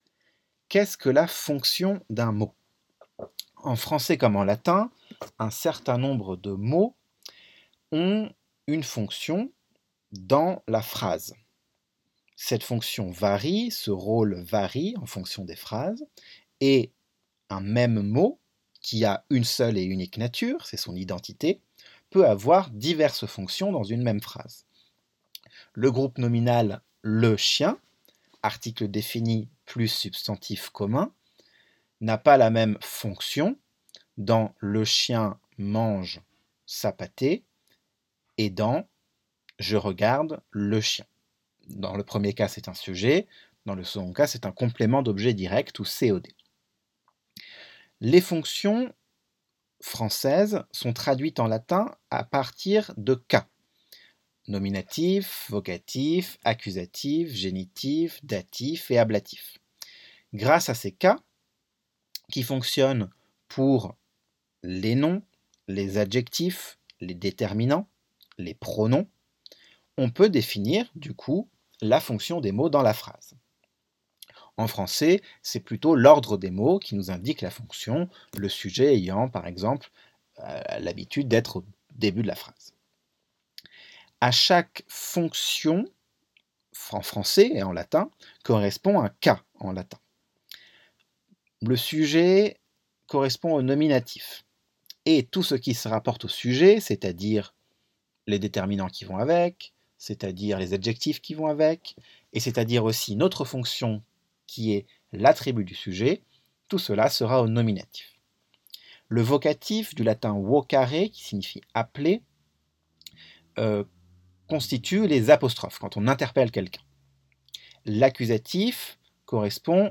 Mémos vocaux pour le latin
Pardon par avance pour les petites hésitations, erreurs de langues et autres zozotements ! =)